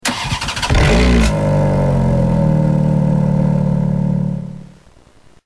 enginestart.mp3